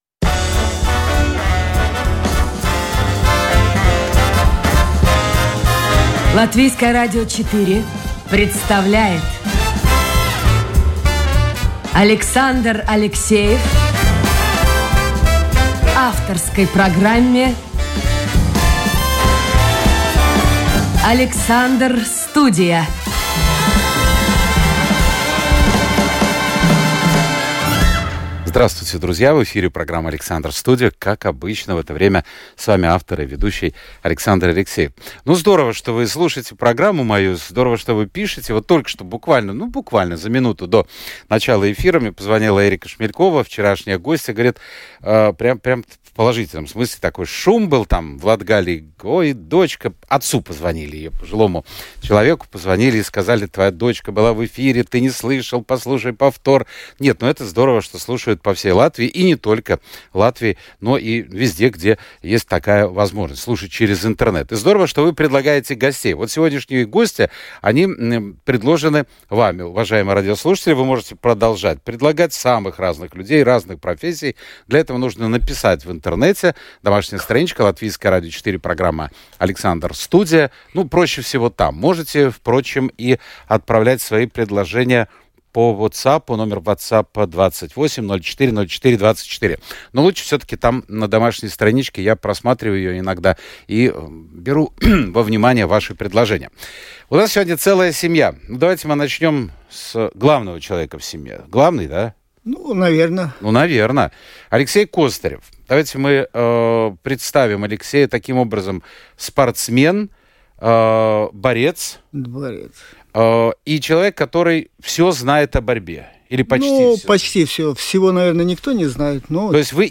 Живой и непринужденный диалог со слушателями, неформальный разговор с известными людьми, ТОК-ШОУ с участием приглашенных экспертов о самых невероятных явлениях нашей жизни.